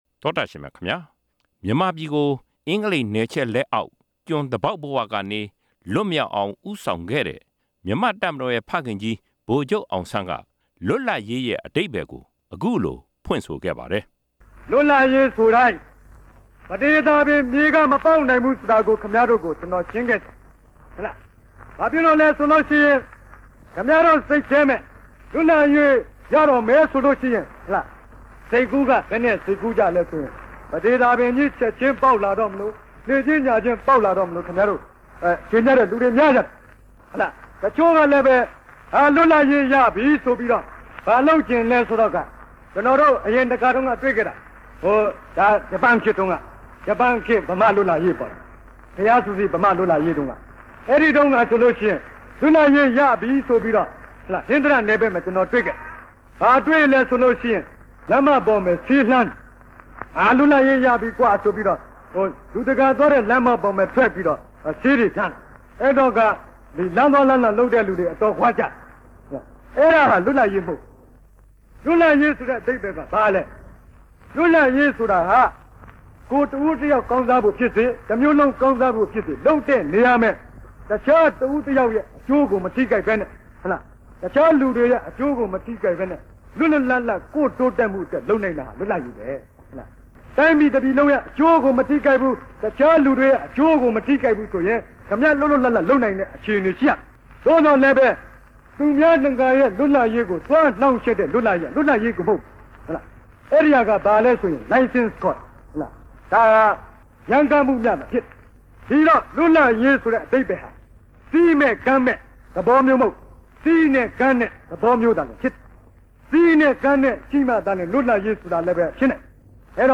ဗိုလ်ချုပ်အောင်ဆန်း မိန့်ခွန်းကောက်နှုတ်ချက်